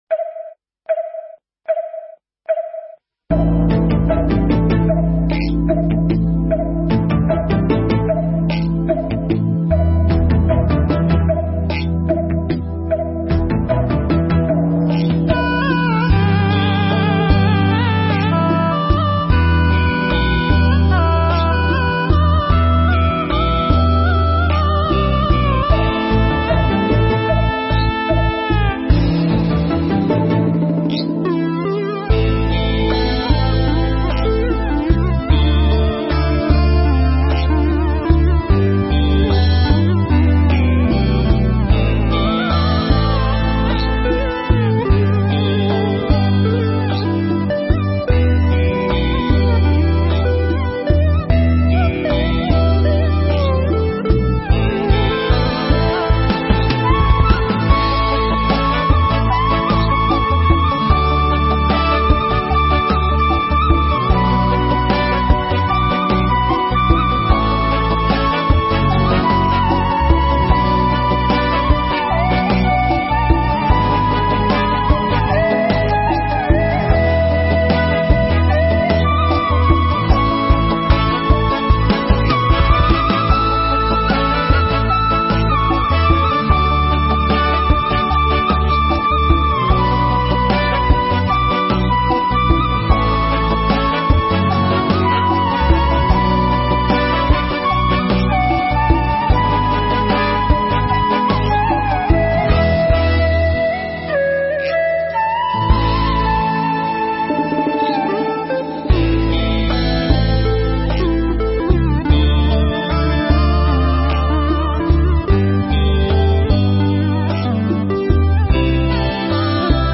Thuyết pháp Phật Ở Đâu
Mp3 Pháp Thoại Phật Ở Đâu (KT76)
giảng trong khóa tu Một Ngày An Lạc lần thứ 76 tại Tu Viện Tường Vân